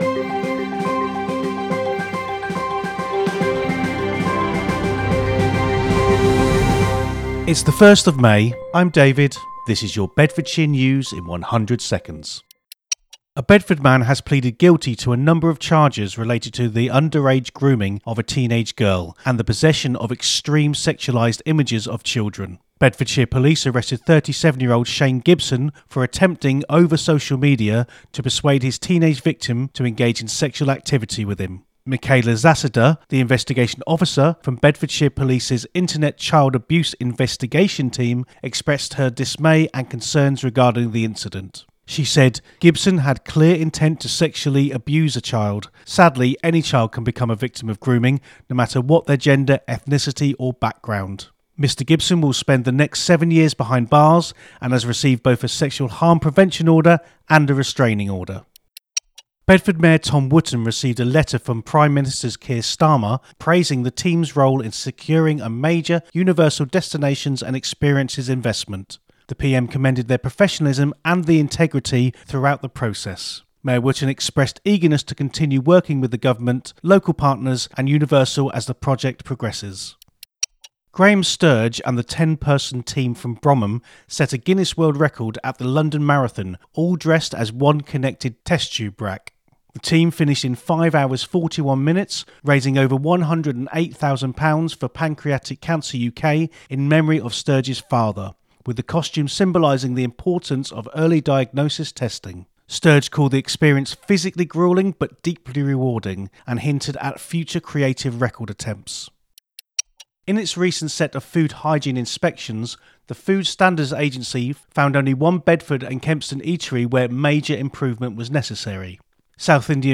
A free audio news roundup for Bedford and the greater Bedfordshire area, every weekday.